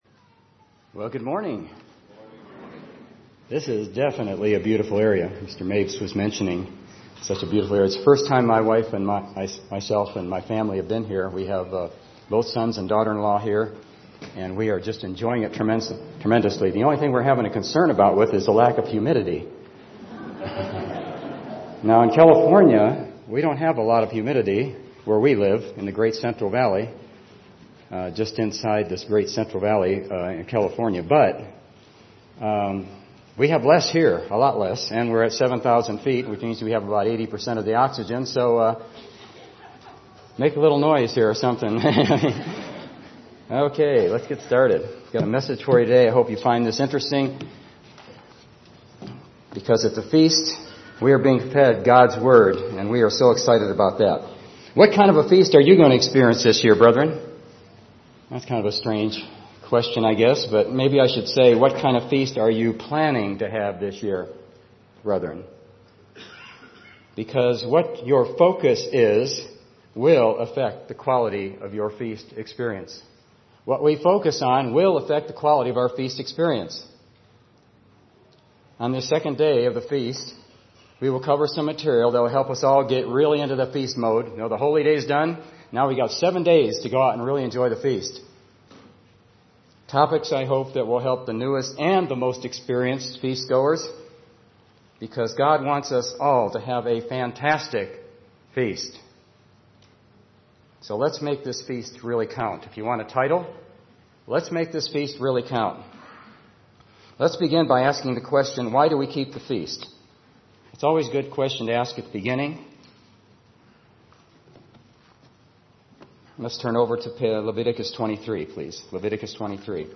This sermon was given at the Steamboat Springs, Colorado 2015 Feast site.